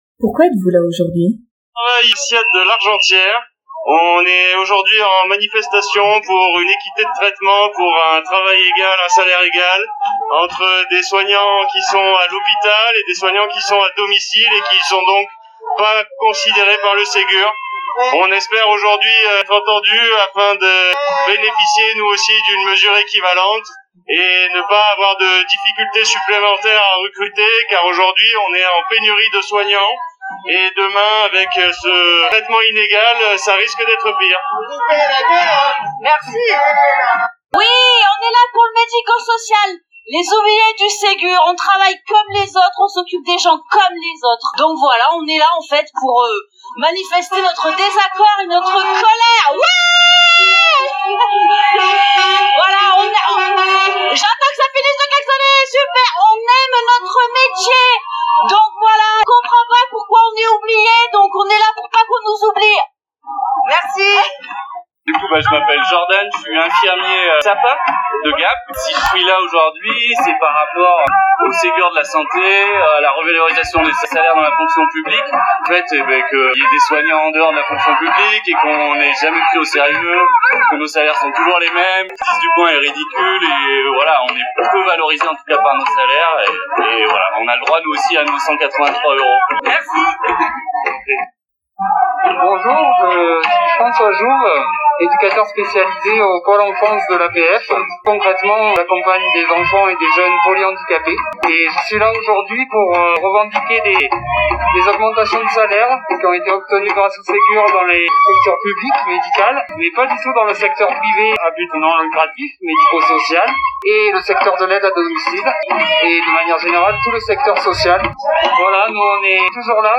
Manifestation seghur.mp3 (2.8 Mo)